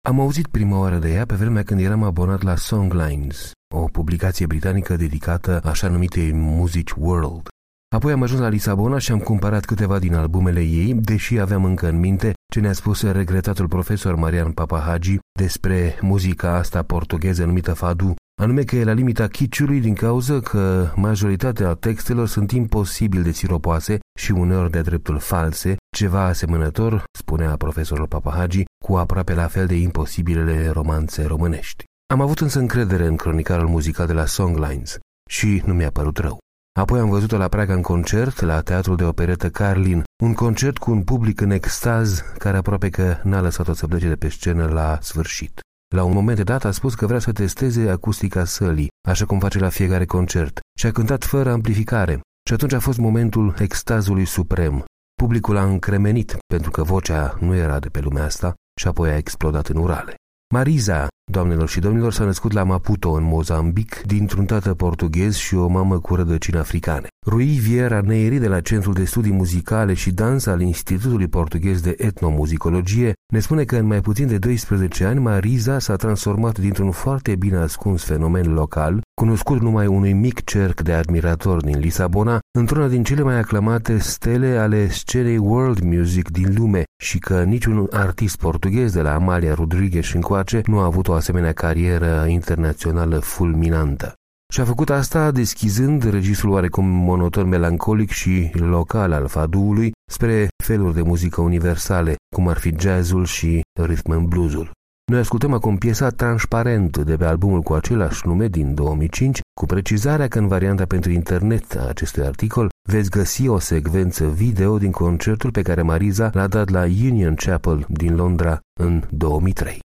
Noi ascultăm acum piesa „Transparente”, de pe albumul cu același nume din 2005, cu precizarea că în varianta pentru internet veți găsi o secvență video din concertul pe care Mariza l-a dat la Union Chapel din Londra în 2003.